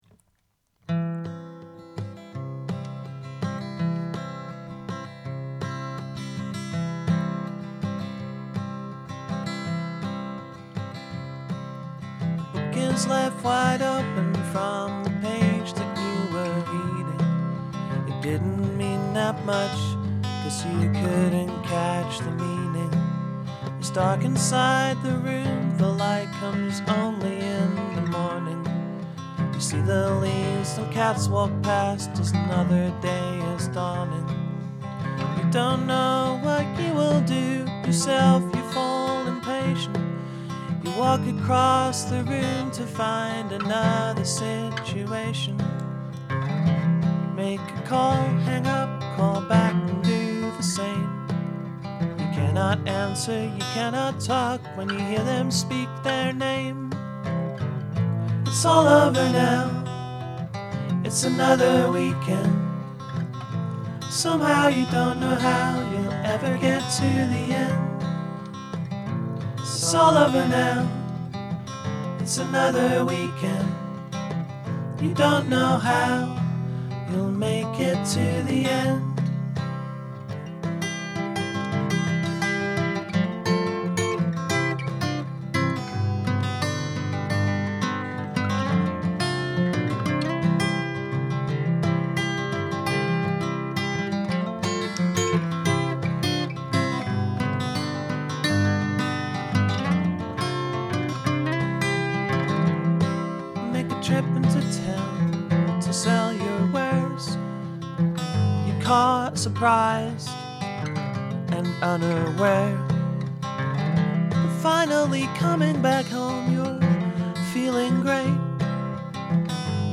here is a demo of a song that may be on my next record.